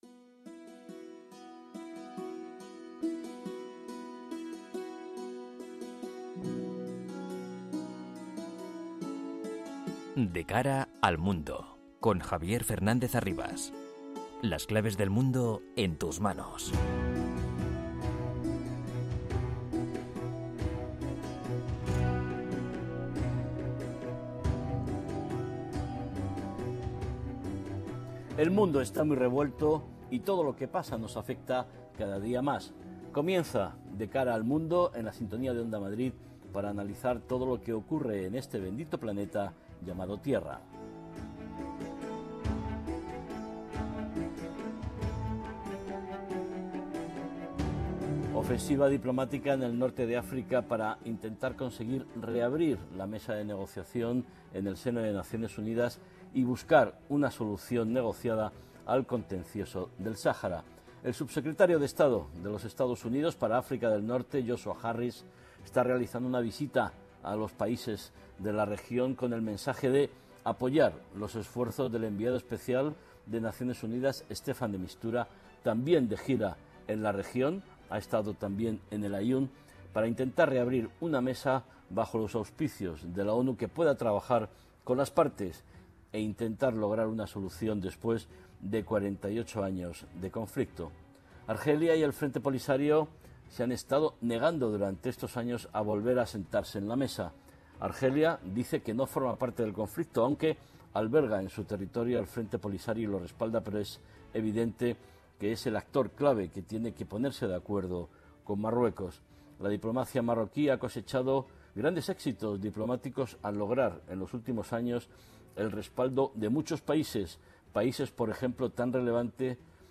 con entrevistas a expertos y un panel completo de analistas